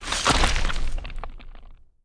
SFX肉迸血溅音效下载
SFX音效